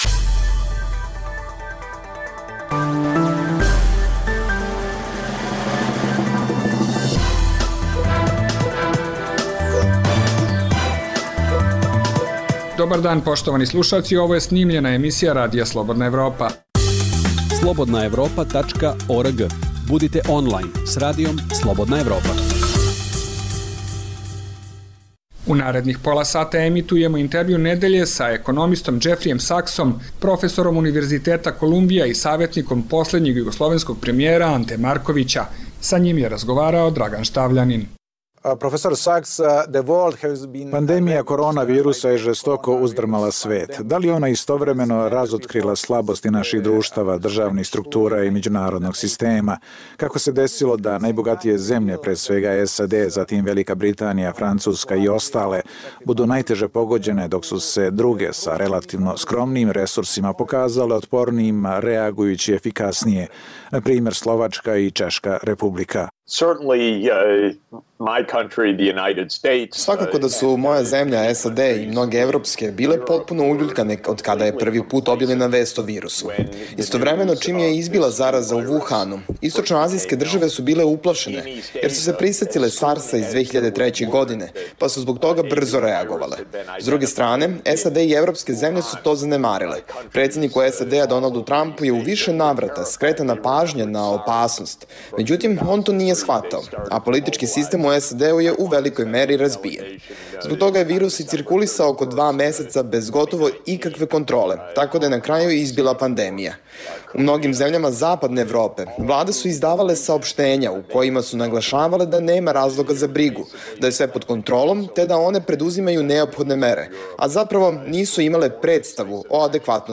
Ovo je program Radija Slobodna Evropa za Srbiju. Zbog pandemije korona virusa ova emisija je unapred snimljena.